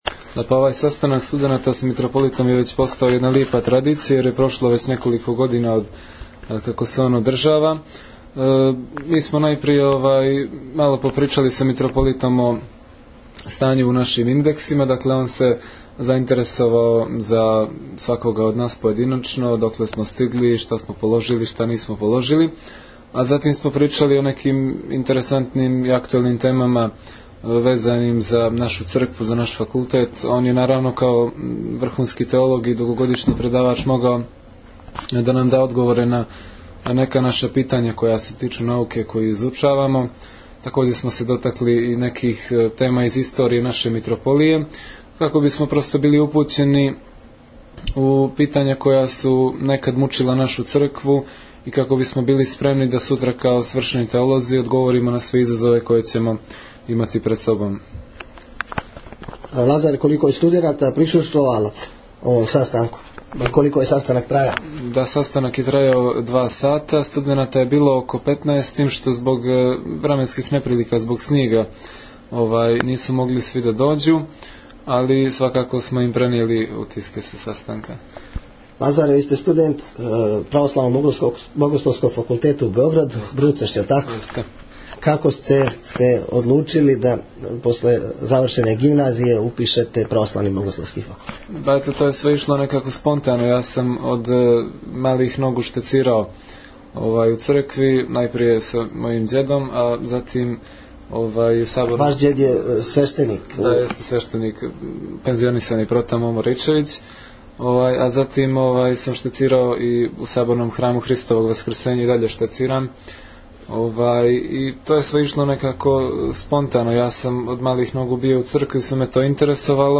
У препуној сали Никшићког позоришта, у понедјељак 30. јануара 2017, у организацији Епархије будимљанско-никшићке, Црквене општине Никшић и Голијског сабора културе, одржана je Светосавска академија.